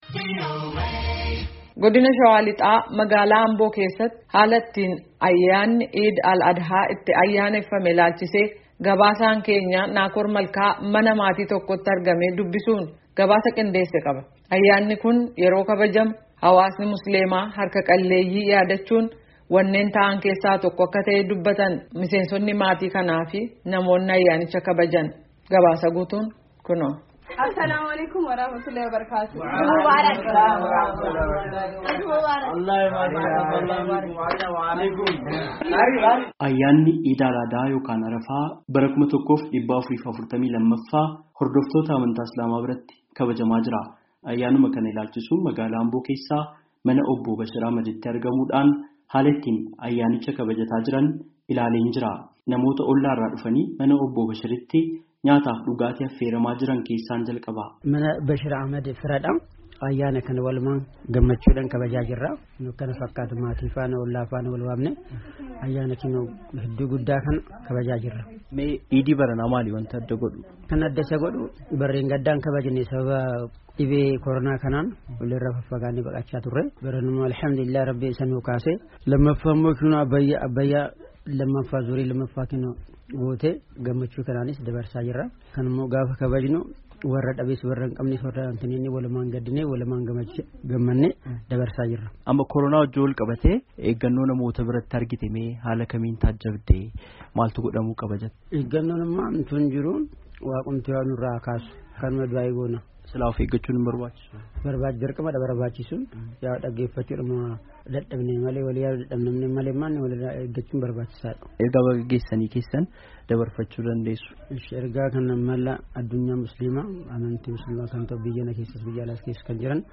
mana maatii tokkootti argamee dubbisuun gabaasaa qindeesse qaba
Ayyaanni kun yeroo kabajamu hawaasni Musliimaa harka qalleeyyii yaadachuun wanneen tahan keessaa tokko akka tahe dubbatan miseensotni maatii kanaa fi namootni ayyaanicha kabajan.